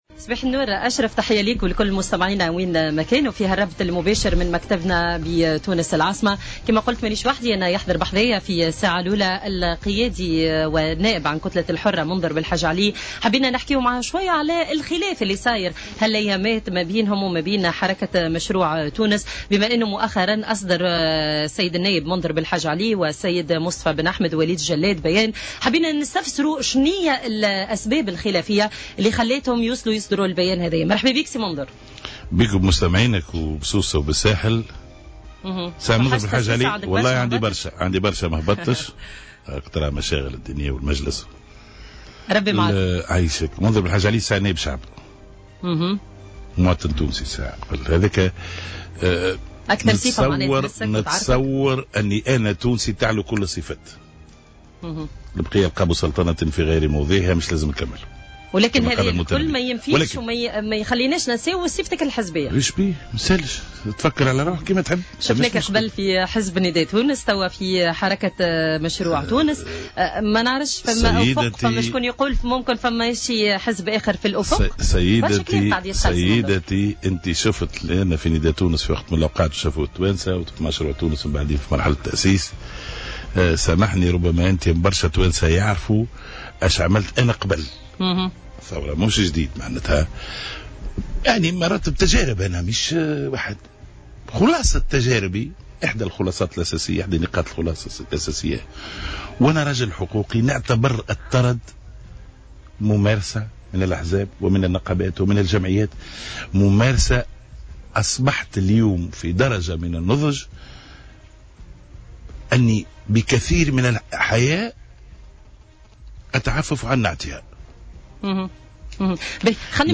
أكد النائب عن كتلة الحرة منذر بلحاج علي ضيف بوليتيكا اليوم الجمعة 28 أكتوبر 2016 البلاد غير مهيأة لانتخابات بلدية حاليا في غياب التشريعات الكفيلة بذلك على حد قوله.